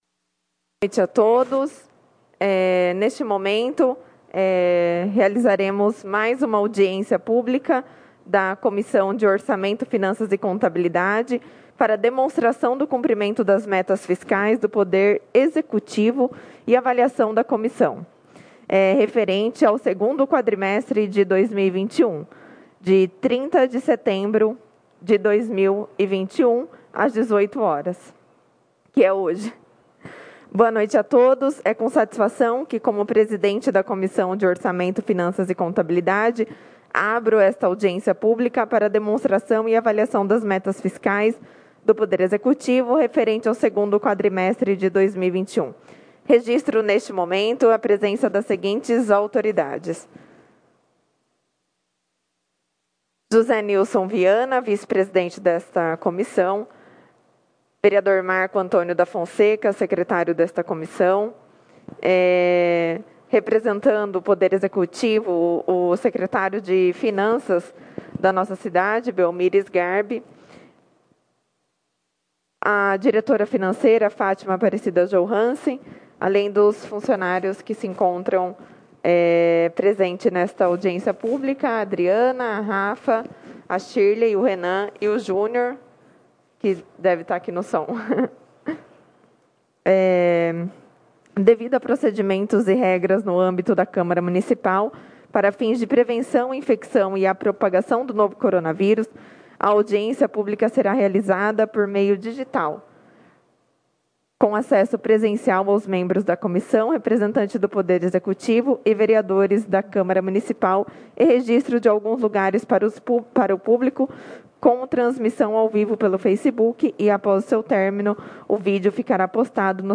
Audiência Pública de 30/09/2021